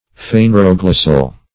Search Result for " phaneroglossal" : The Collaborative International Dictionary of English v.0.48: Phaneroglossal \Phan`er*o*glos"sal\, a. [Gr. fanero`s evident + glw`ssa tongue.]
phaneroglossal.mp3